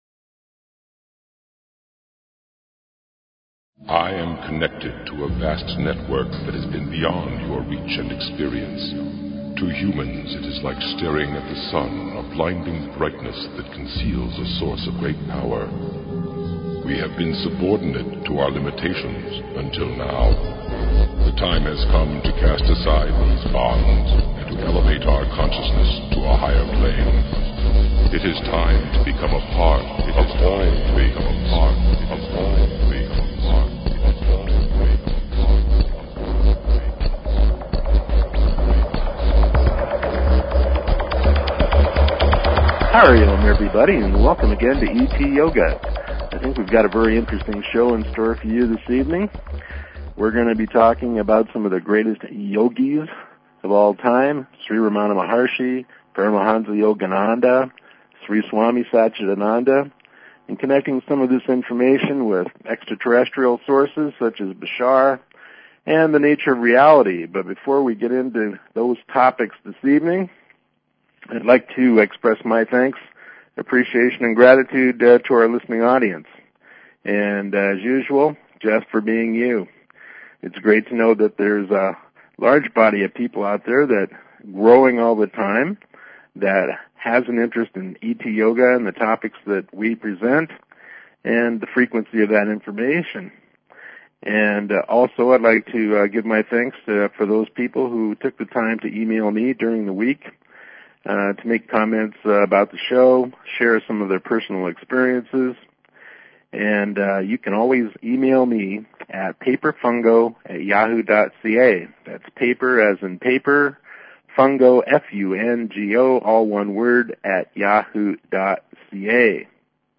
Please consider subscribing to this talk show.